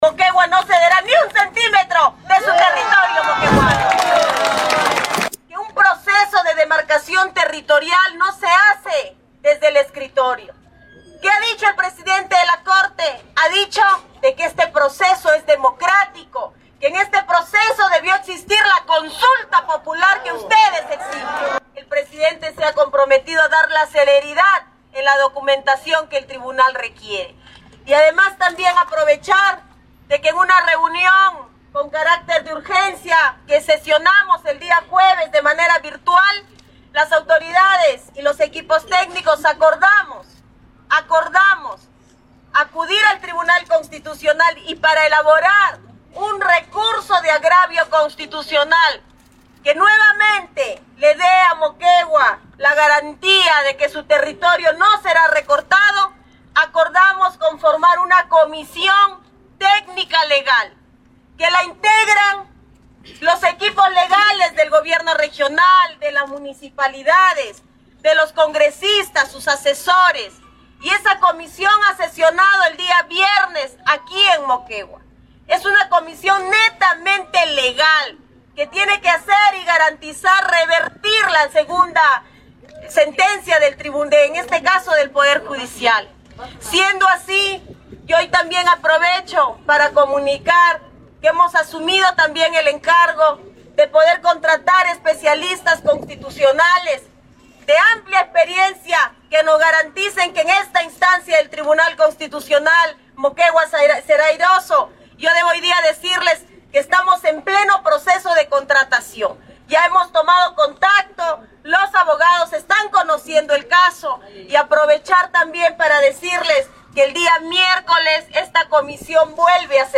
GOBERNADORA.mp3